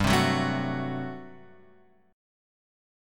GM7sus2sus4 chord {3 0 0 0 1 2} chord